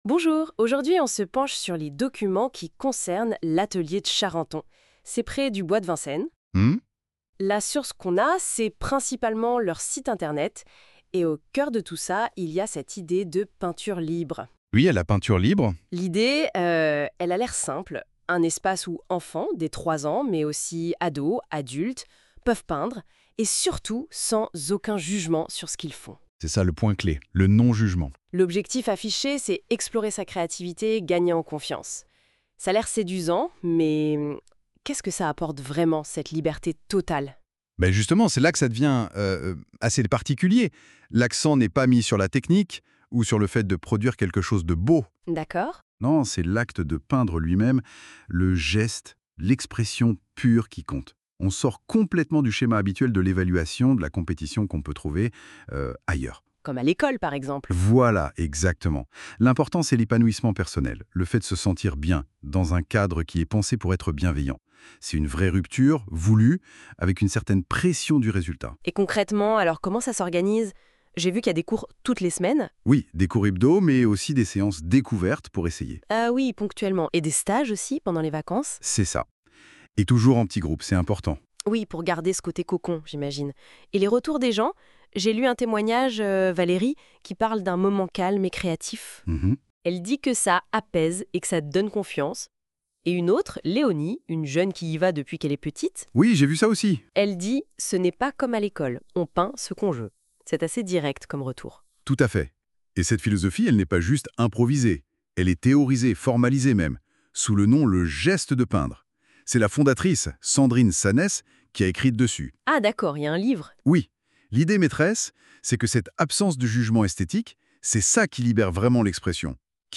Curieux de voir comment la technologie perçoit notre travail, nous avons lancé un projet inédit : nous avons fourni des sources sur l’Atelier de Charenton à une IA, qui a généré un résumé audio sous forme de podcast.
Le résultat est étonnant : l’IA a créé une conversation animée, entre un homme et une femme qui explorent l’univers de l’Atelier. L’originalité de cette retranscription réside dans cette interview imaginaire : l’animateur découvre l’atelier et son invitée lui en explique les spécificités. Un dialogue complice s’établit, presque comme si vous écoutiez l’émission en direct.